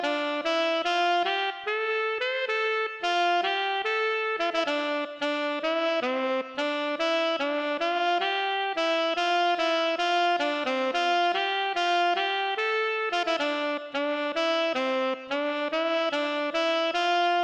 Tag: 110 bpm House Loops Brass Loops 2.94 MB wav Key : Unknown